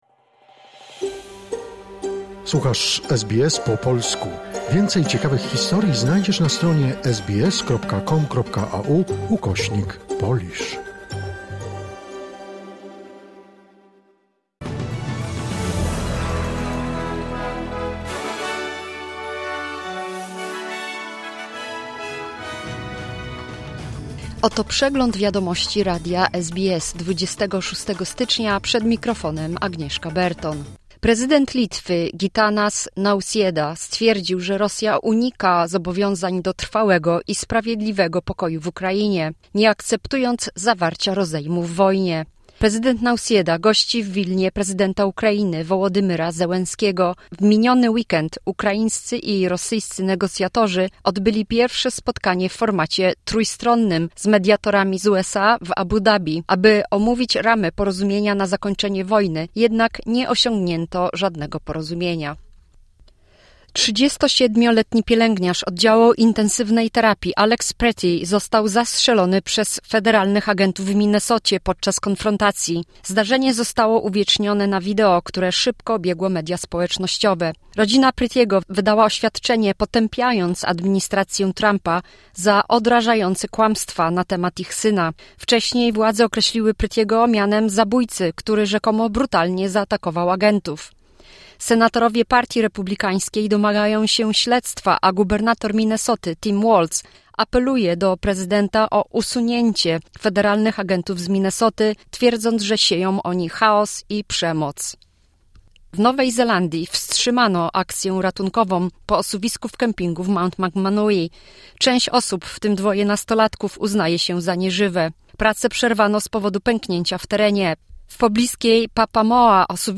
Wiadomości 26 stycznia 2026 SBS News Flash